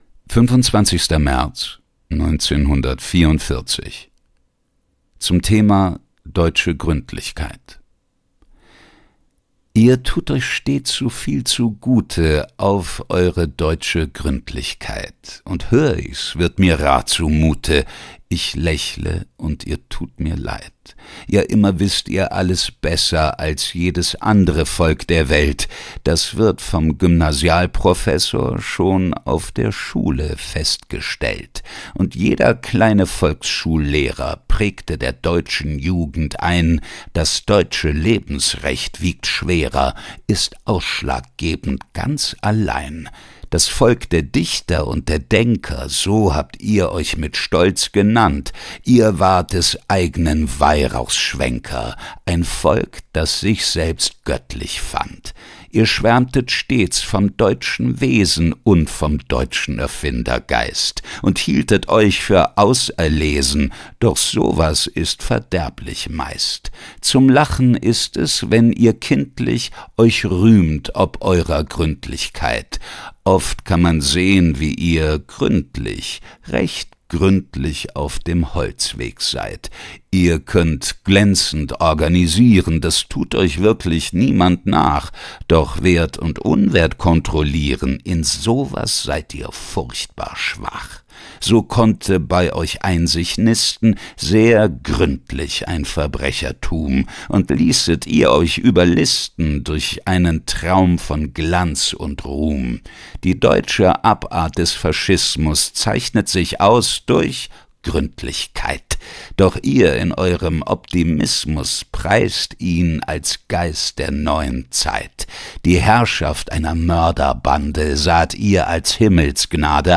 Lesung